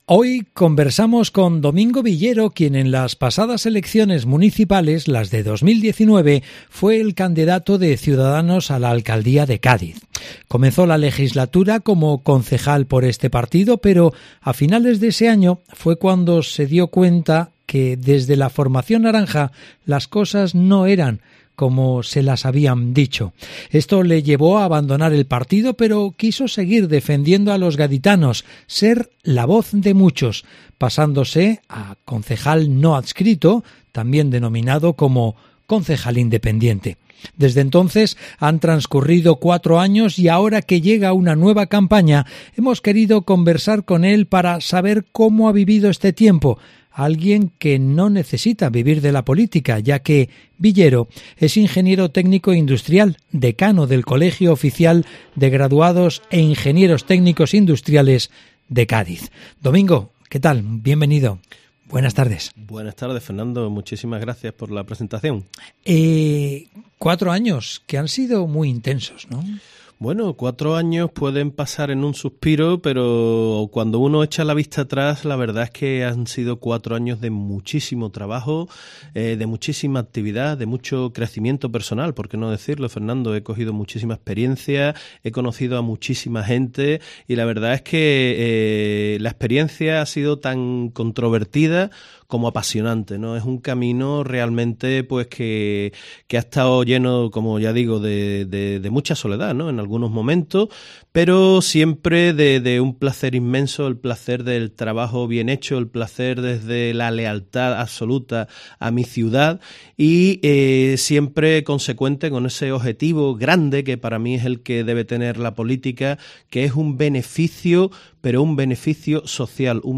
Entrevista A Domingo Villero, Concejal independiente del Ayuntamiento de Cádiz